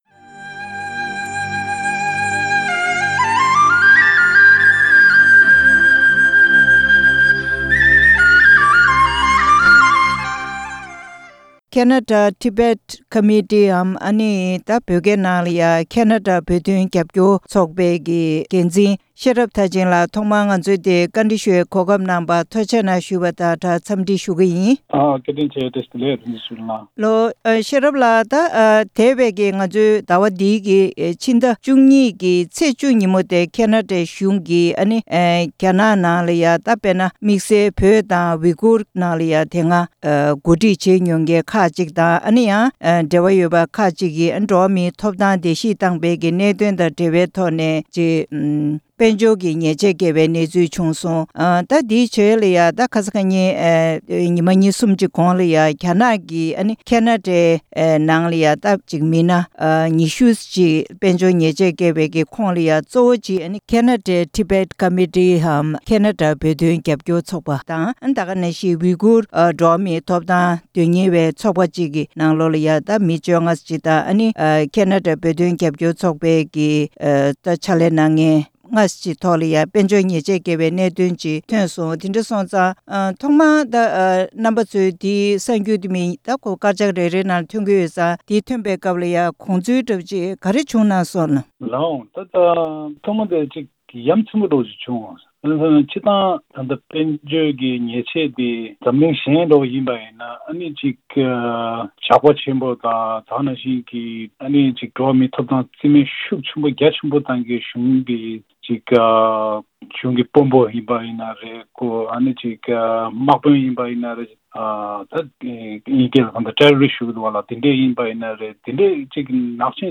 བཅར་འདྲི་གནང་པར་གསན་རོགས།